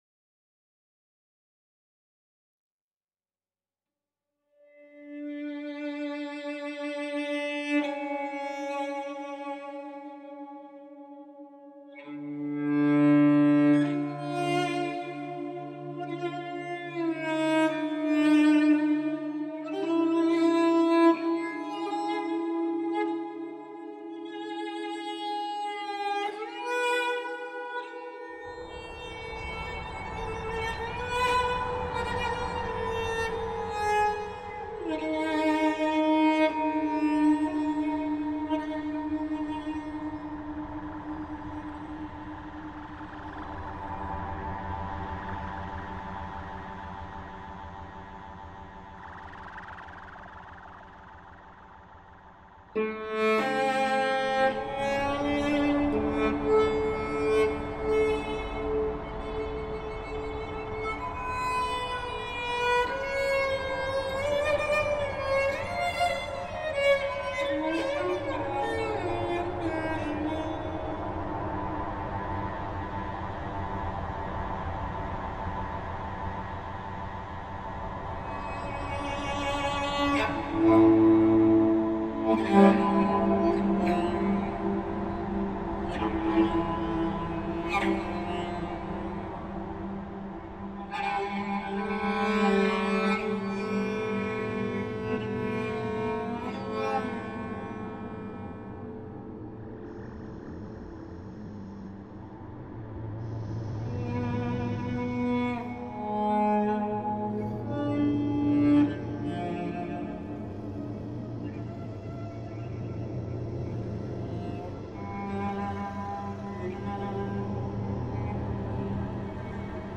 for cello and electronics
Cello
Recorded and mixed in Lille & Paris